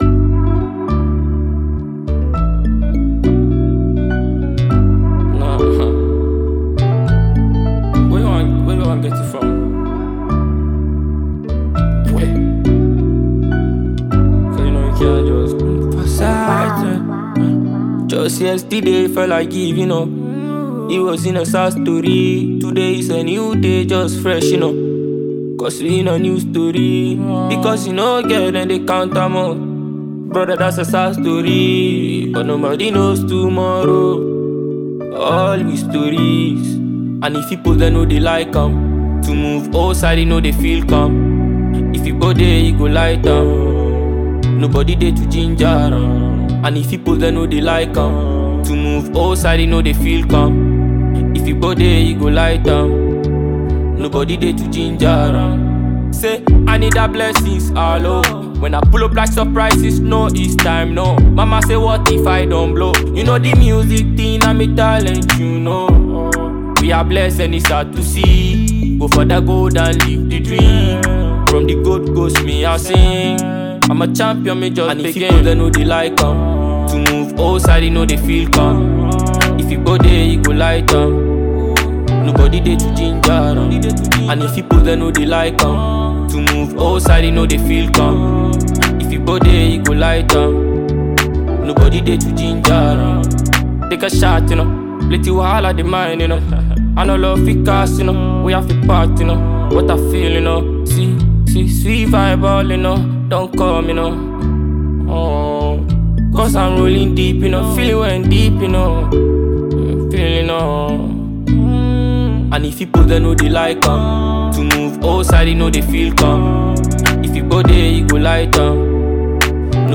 Ghanaian dancehall artiste